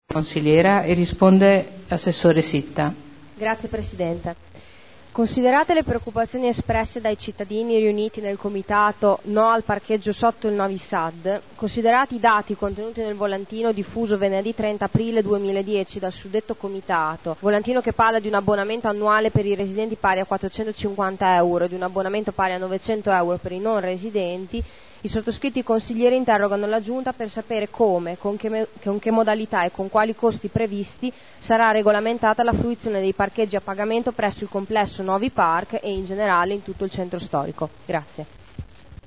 Giulia Morini — Sito Audio Consiglio Comunale